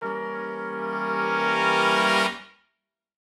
Index of /musicradar/gangster-sting-samples/Chord Hits/Horn Swells
GS_HornSwell-Edim.wav